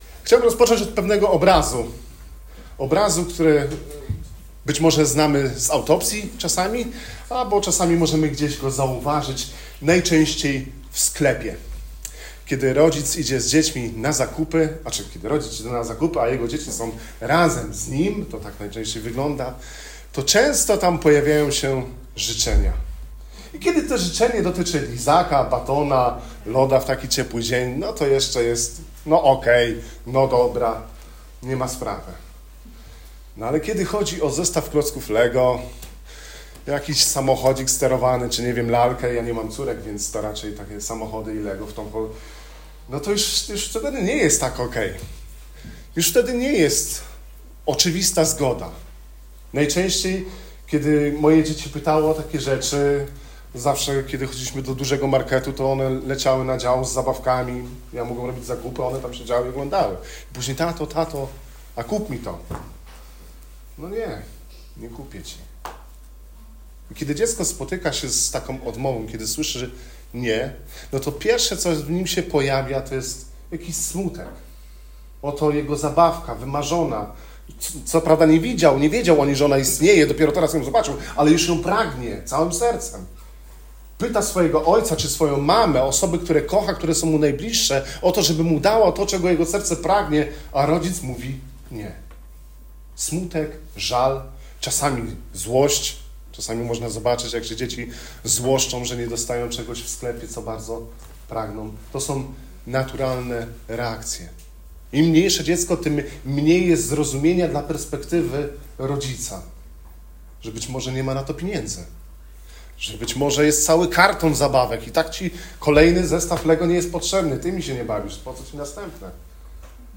To kazanie pomoże Ci spojrzeć na trudne odpowiedzi Pana w nowym świetle: jako wyraz Jego mądrości, miłości i troski. Usłyszysz, dlaczego Jego „nie” bywa większym błogosławieństwem niż nasze „tak” i jak uczyć się ufać Ojcu, który naprawdę wie, co jest dla nas dobre.